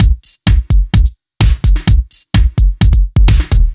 Index of /musicradar/french-house-chillout-samples/128bpm/Beats
FHC_BeatA_128-02.wav